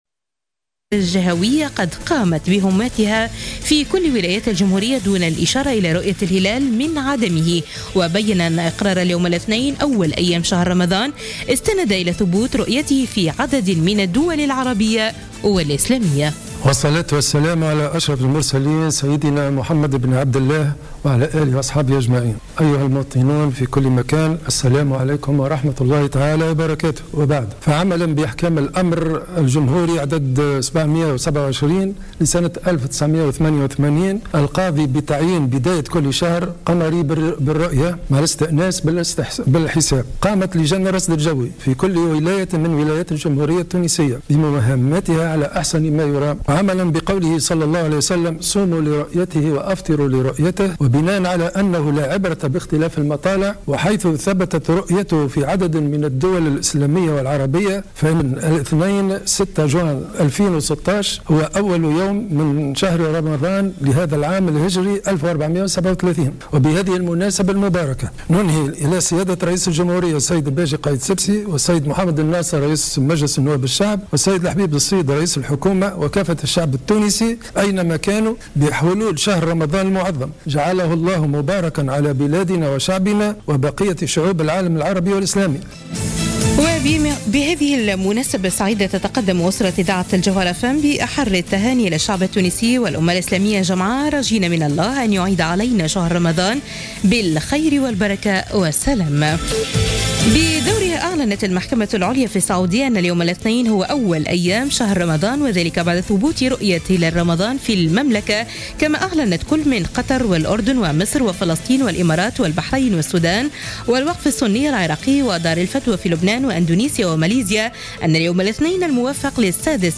نشرة أخبار منتصف الليل ليوم الإثنين 6 جوان 2016